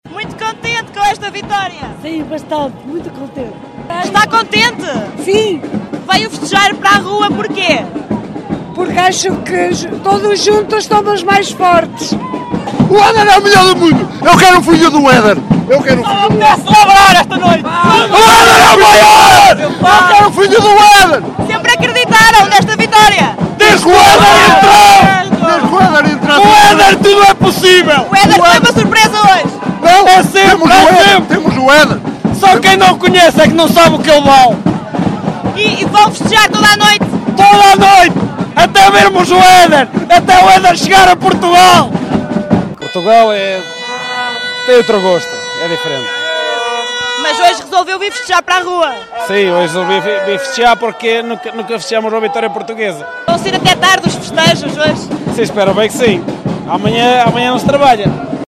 Antes mesmo da taça estar nas mãos de Cristiano Ronaldo, que saiu lesionado, a festa começou a sair à rua em Macedo de Cavaleiros, e foram centenas os que não quiseram ficar de fora.
vox-pop-europeu.mp3